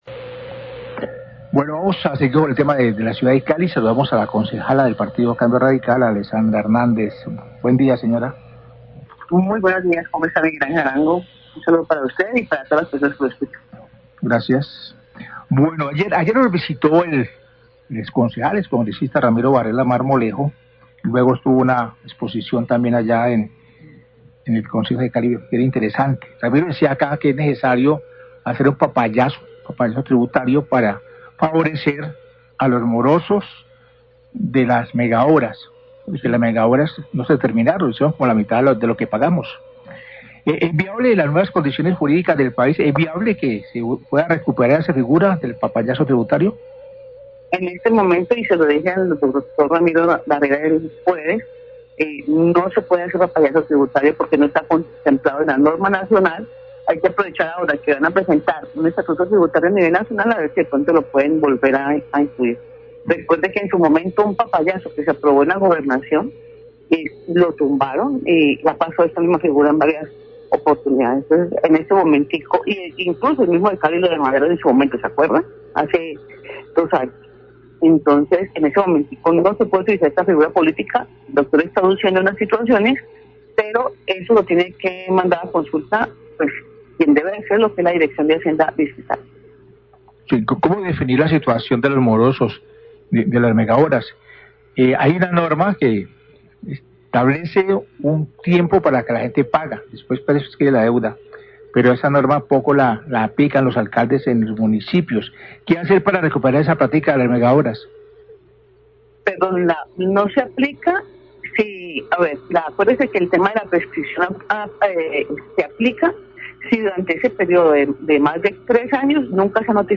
Radio
NOTICIERO RELÁMPAGO